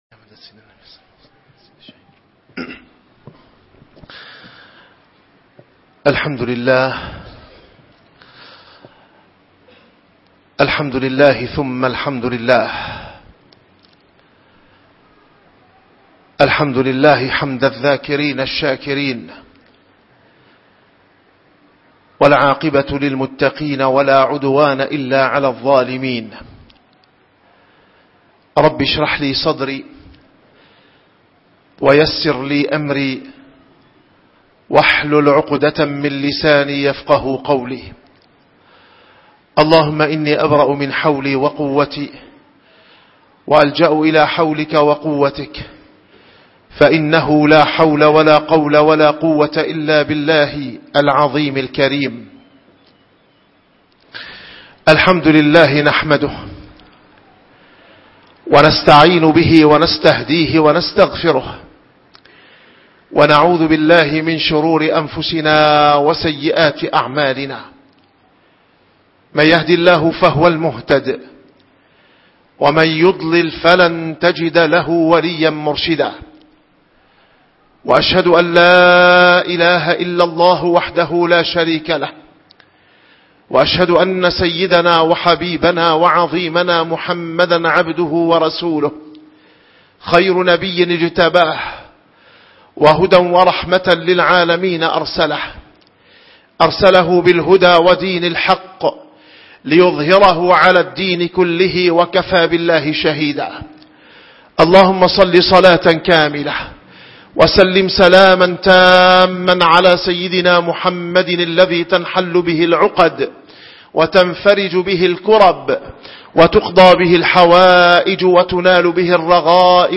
- الخطب - حسن الظن من الإيمان 8 - من بواعث الغيبة الحقد 2